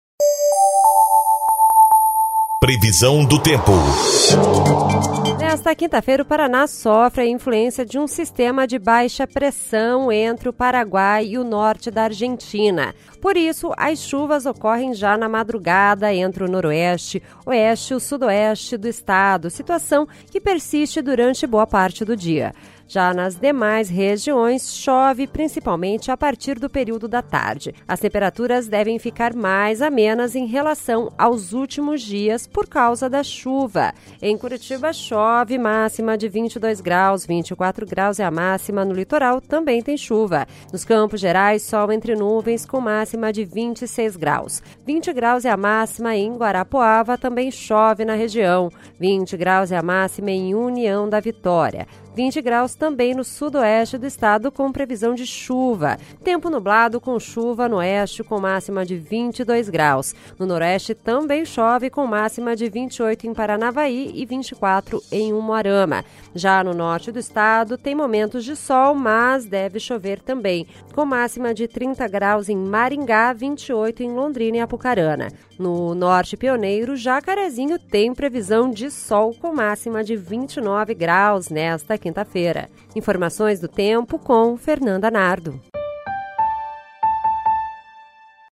Previsão do Tempo (06/04)